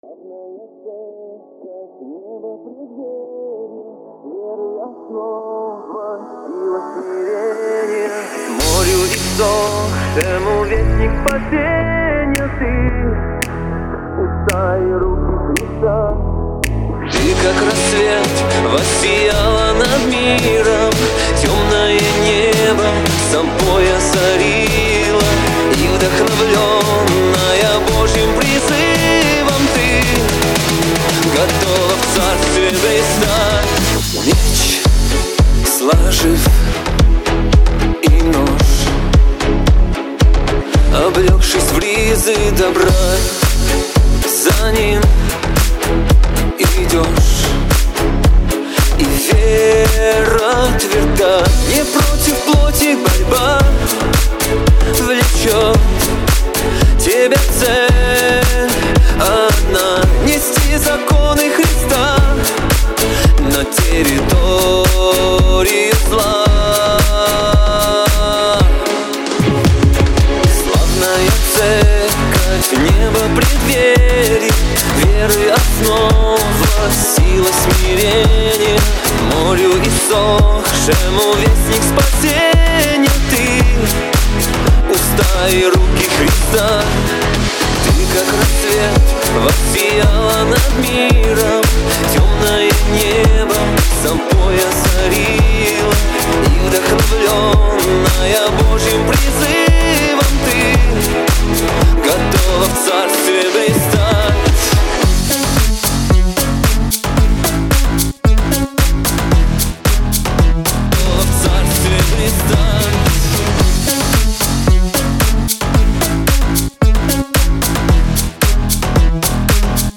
662 просмотра 1980 прослушиваний 141 скачиваний BPM: 112